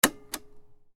Stop Button Sound Effect
The sound of pressing a mechanical plastic stop button on a cassette deck device. Button sounds.
Stop-button-sound-effect.mp3